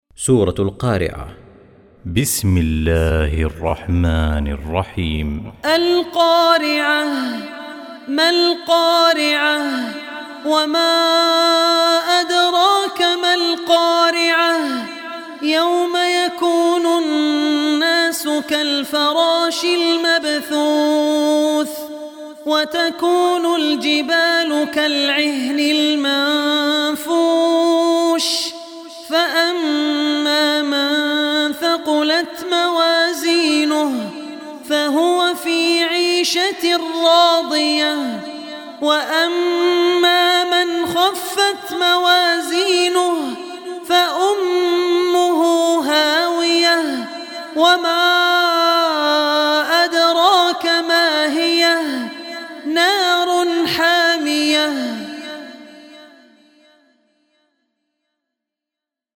Surah Qariah Recitation by Abdur Rehman Al Ossi
Surah Qariah, listen online mp3 tilawat / recitation in the voice of Sheikh Abdur Rehman Al Ossi.
101-Surah-qariah.mp3